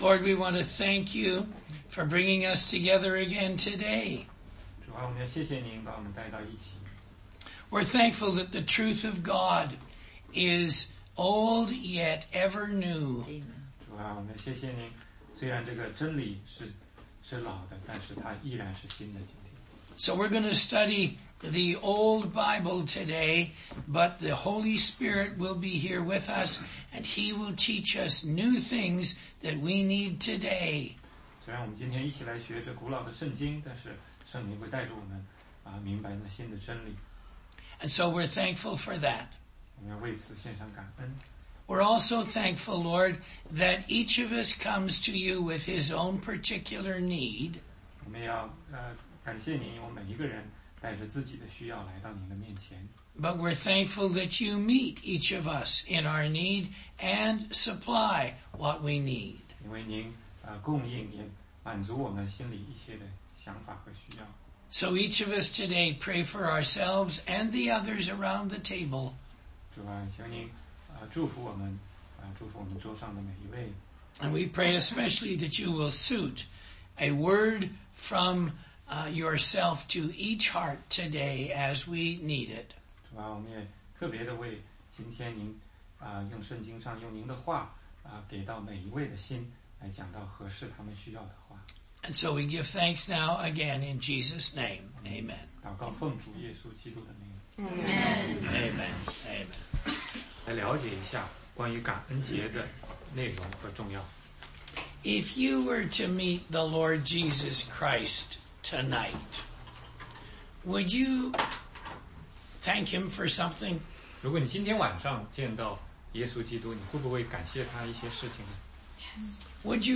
16街讲道录音 - 关于感恩节
答疑课程